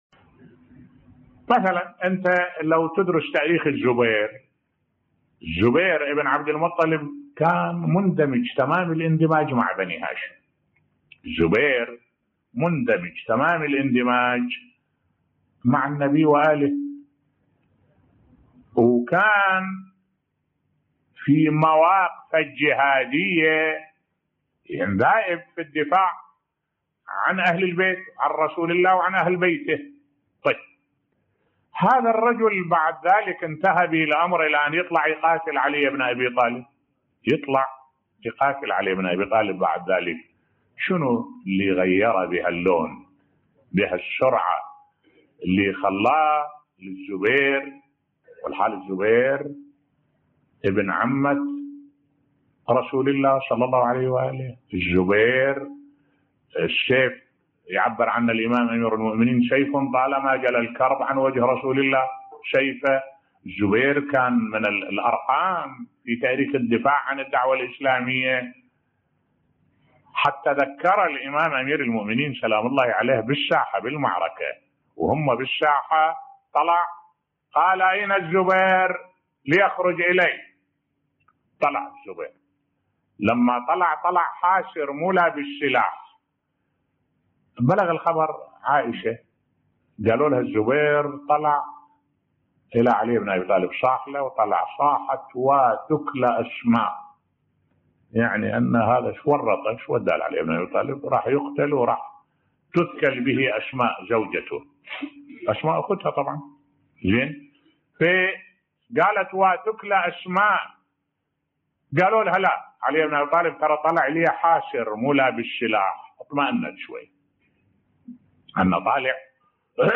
ملف صوتی سوء خاتمة الزبير بن العوام بصوت الشيخ الدكتور أحمد الوائلي